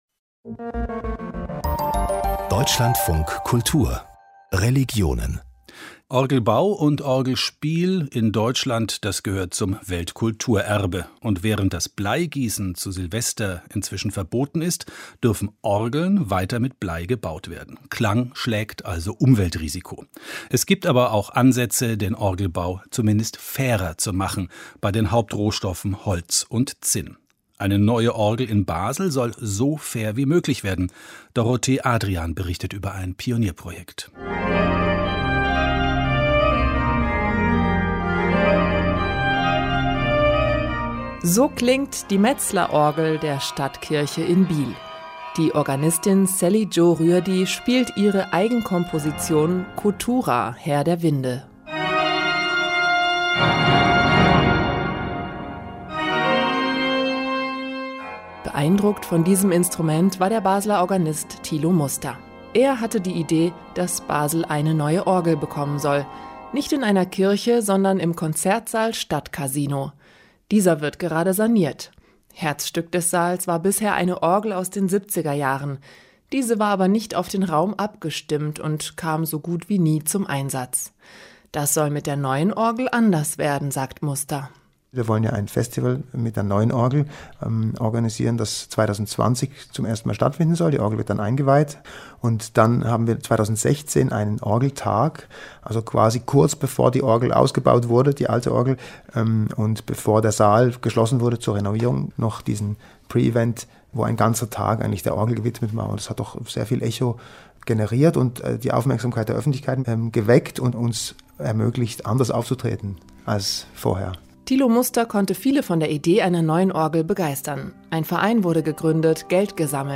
Beitrag im Deutschlandfunk über die geplante neue Orgel im Stadtcasino Basel und nachhaltiges Zinn: